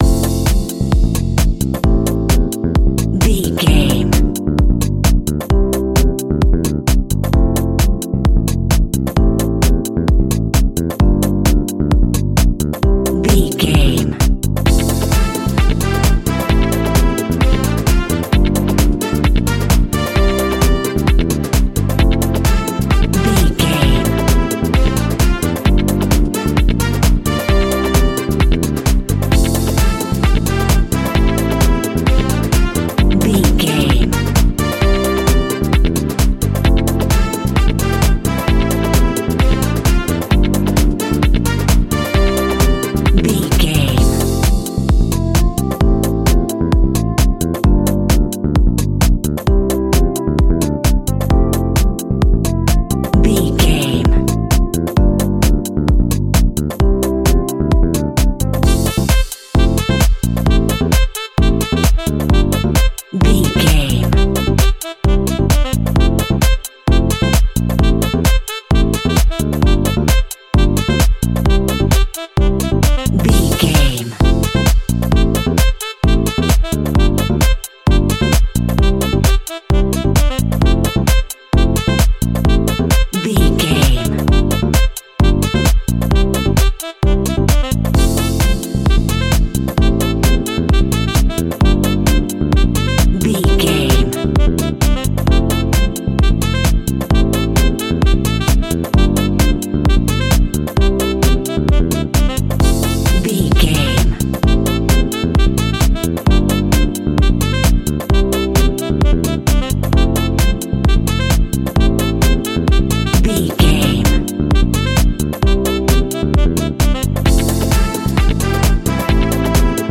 Ionian/Major
groovy
uplifting
energetic
bass guitar
brass
saxophone
drums
electric piano
electric guitar
electro
deep house
nu disco
synth
upbeat
funky guitar
clavinet
synth bass
funky bass